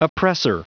Prononciation du mot oppressor en anglais (fichier audio)
Prononciation du mot : oppressor